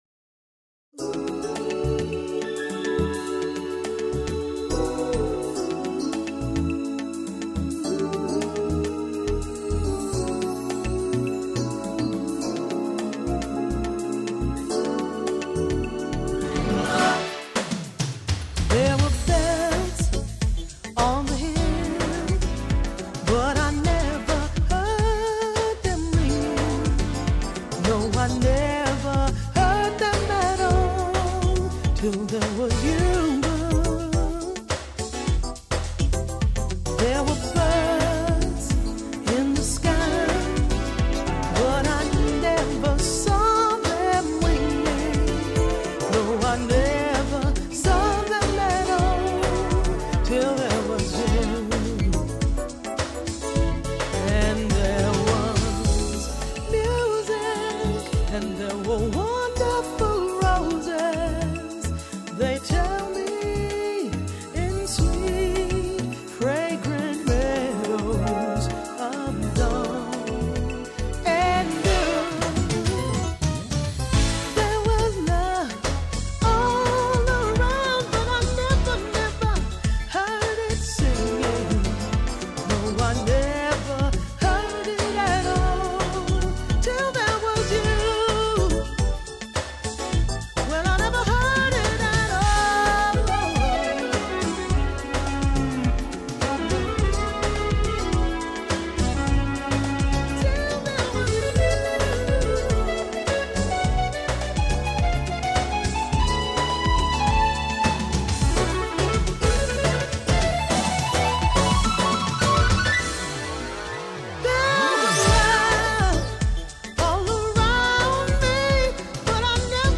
Tags: Sentimental Classic All time favorite Sweet Passionate